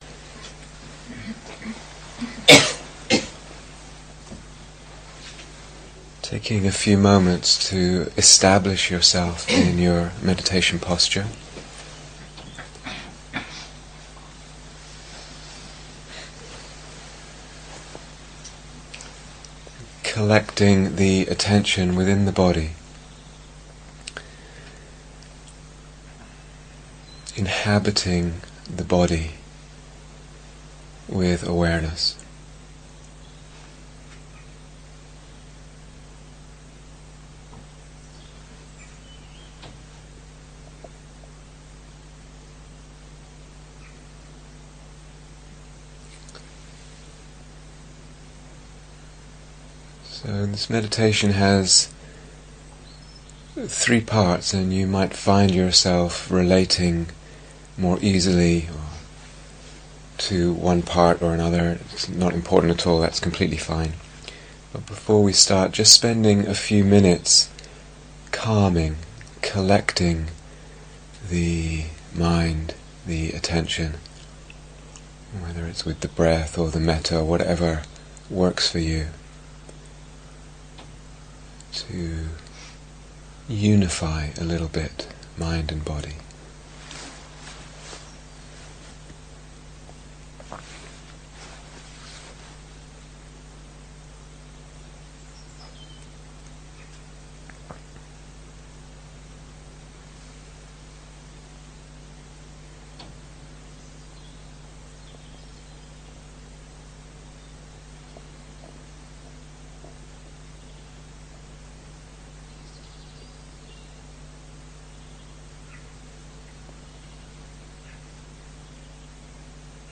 Guided Meditation: Three Characteristics